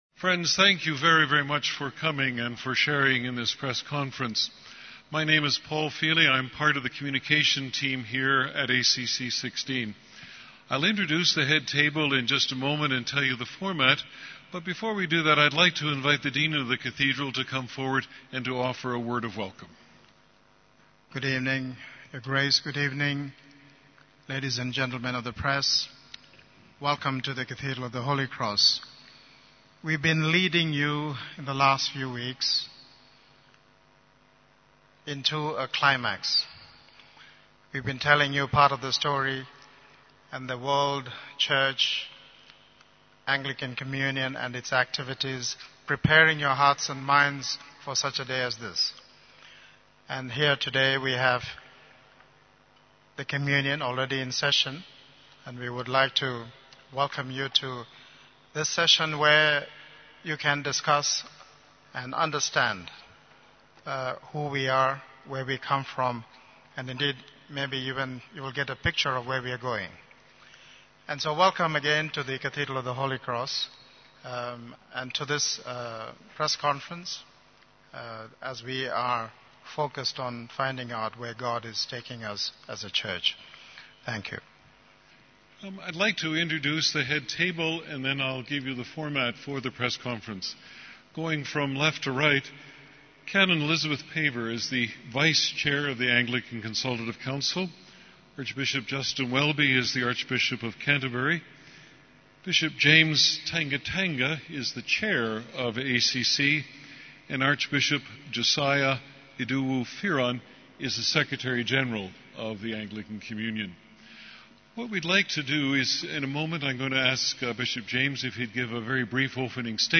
ACC-16 Press Conference 8th April 2016